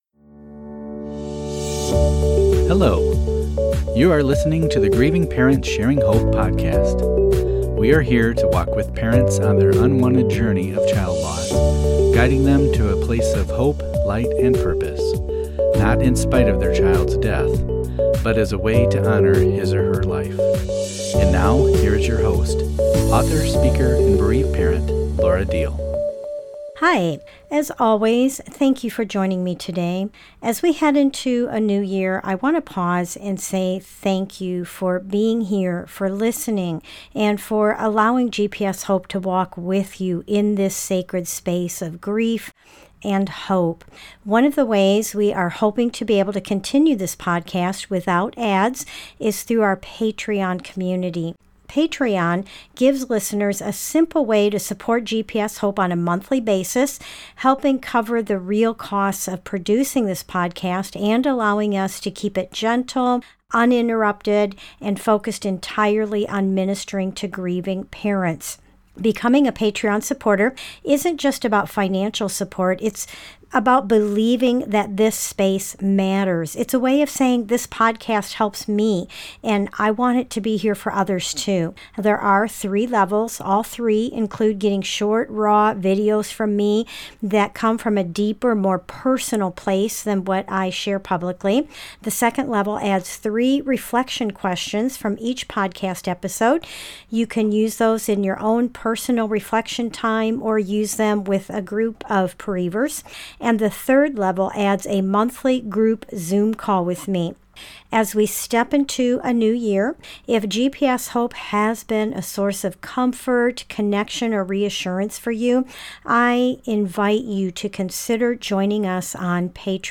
A Christmas Prayer for Bereaved Parents a GPS Hope podcast episode offering prayer, pause, and hope for parents grieving a child at Christmas